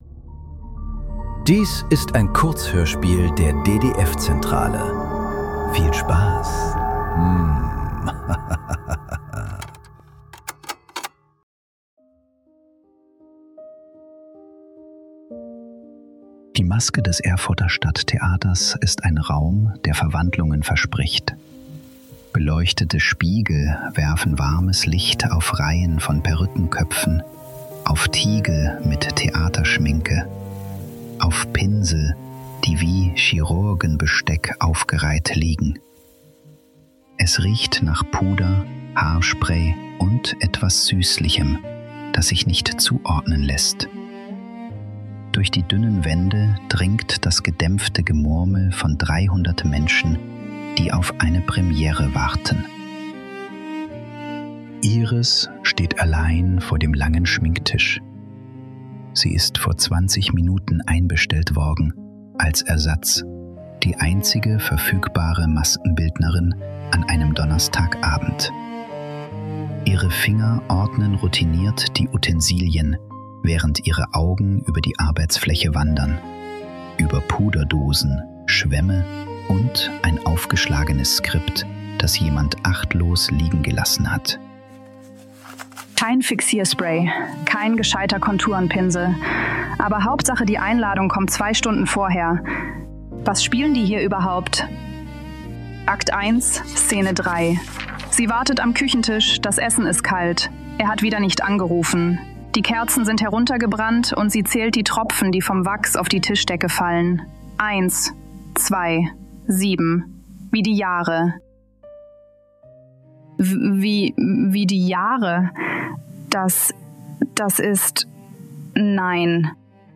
Die Maske ~ Nachklang. Kurzhörspiele.